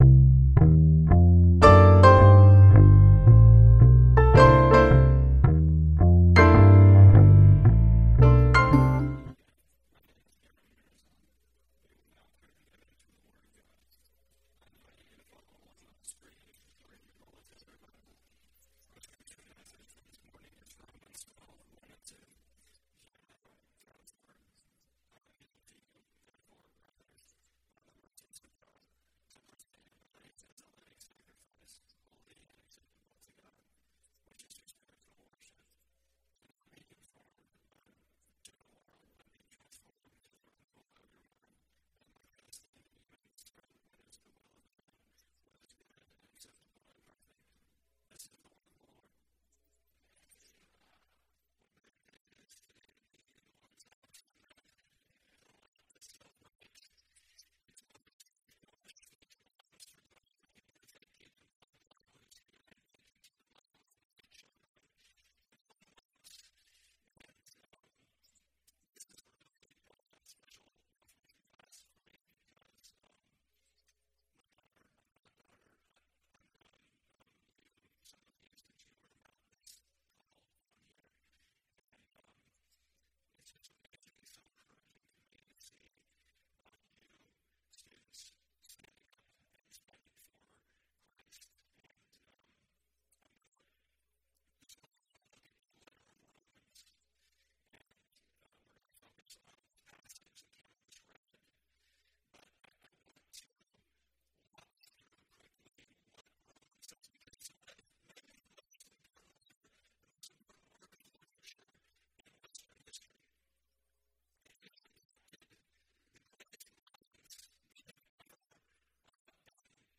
Single Series Sermon Passage: Romans 12:1-2 Service Type: Sunday Worship « What Is Love?